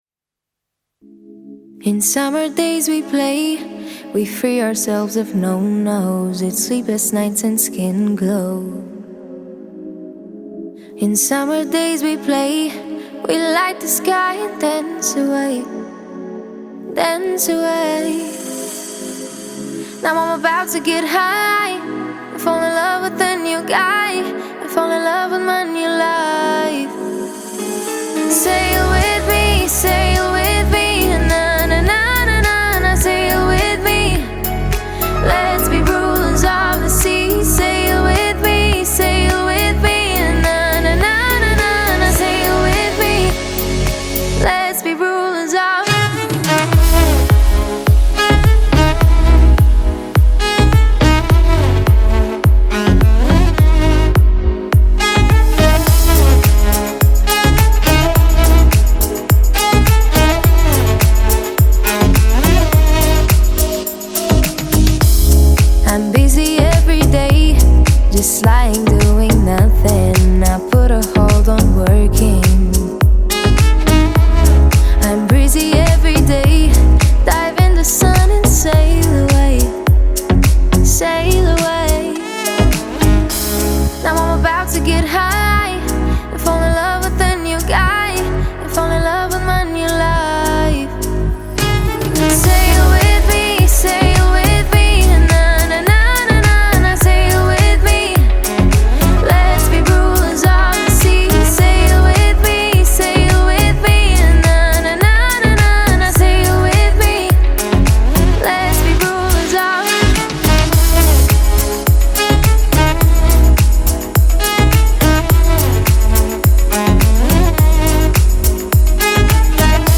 яркая и мелодичная композиция в жанре progressive house